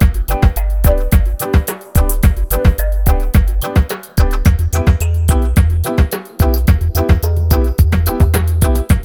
RAGGALOOP4-L.wav